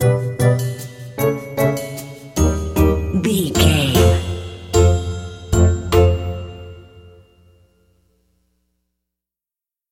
Ionian/Major
F#
Slow
orchestra
strings
flute
drums
circus
goofy
comical
cheerful
perky
Light hearted
quirky